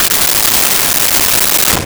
Splash Hard 02
Splash Hard 02.wav